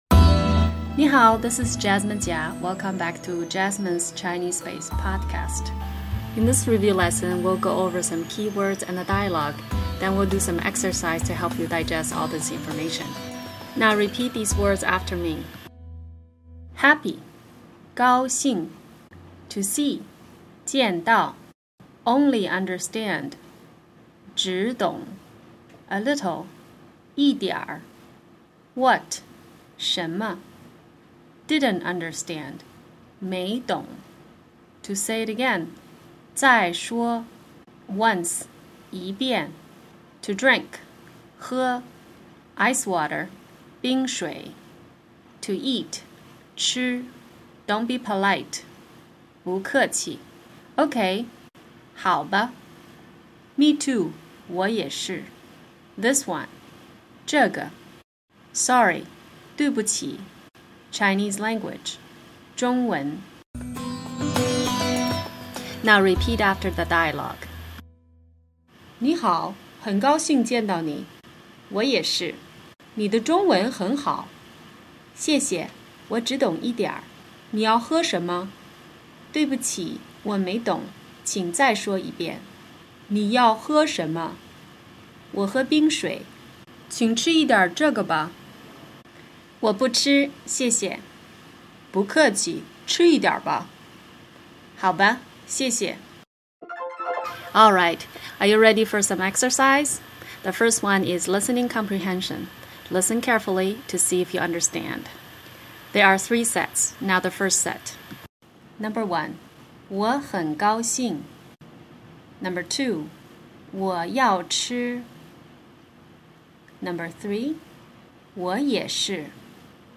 Review phrases and a dialogue related to being a guest. Listening comprehension and translation exercise.